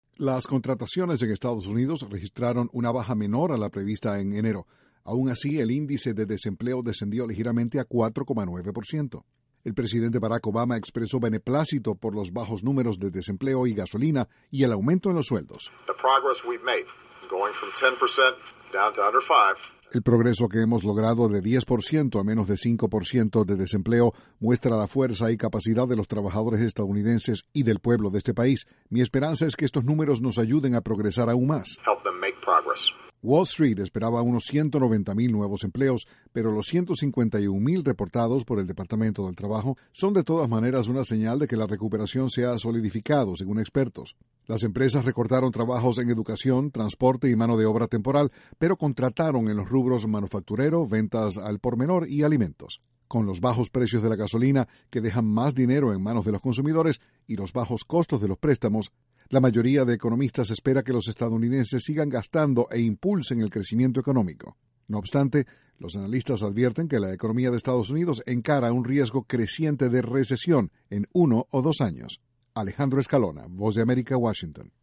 Según cifras oficiales, el desempleo en Estados Unidos bajó a su menor nivel en 8 años. Desde la Voz de América, Washington